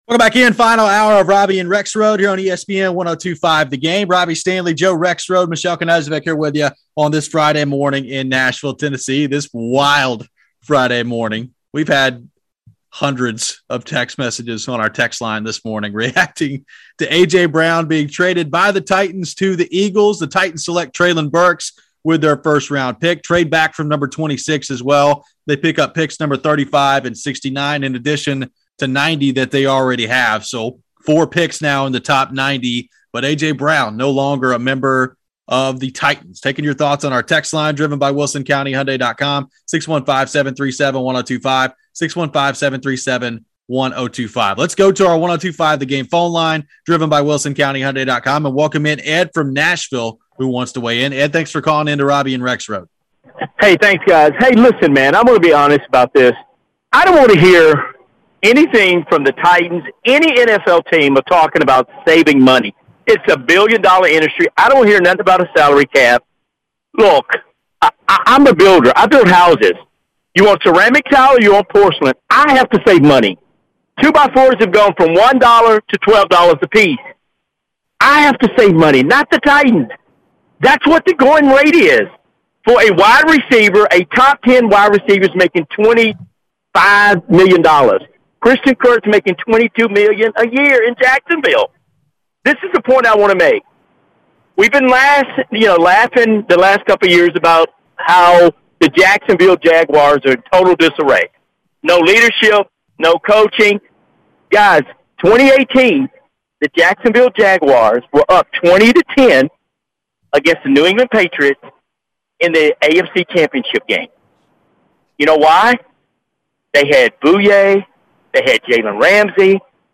The guys take calls about the trade. Later in the hour is weekend winners.